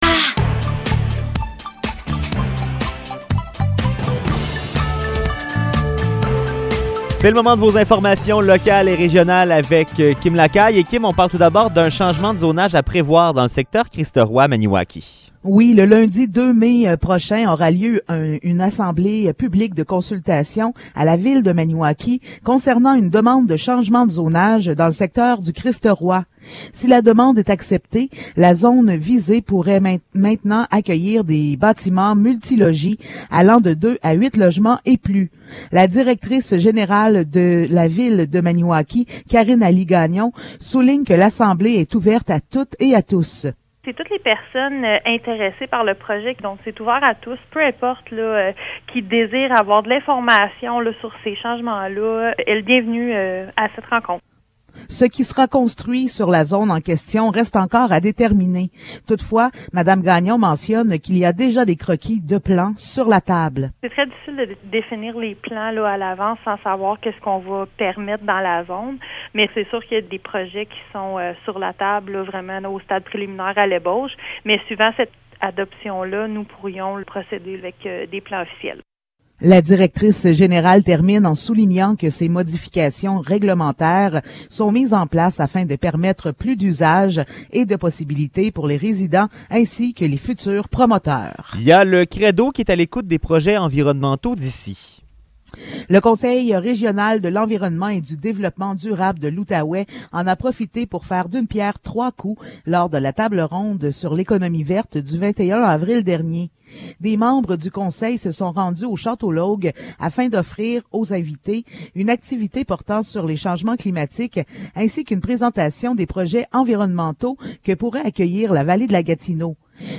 Nouvelles locales - 29 avril 2022 - 16 h